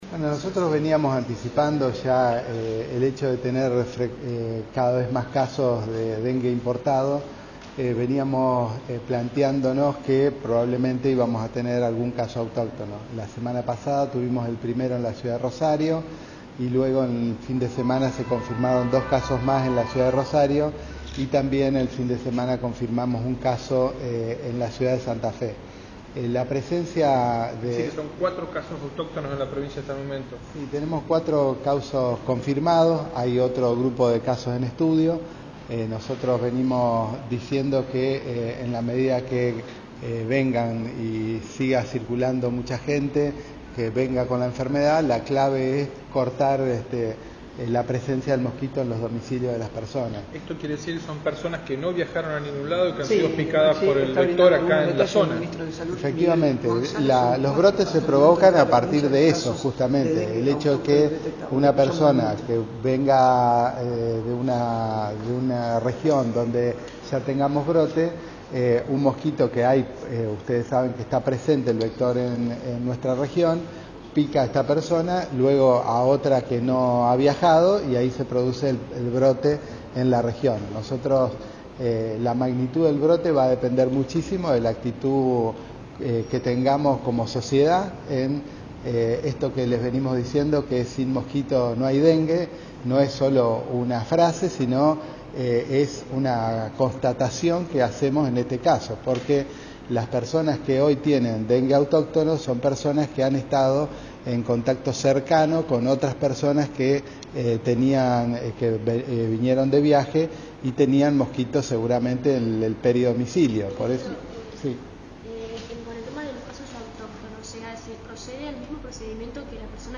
Salud El ministro de Salud, Miguel González, durante la conferencia realizada en la sede de la cartera sanitaria.
Fue durante una conferencia realizada en la sede de la cartera sanitaria.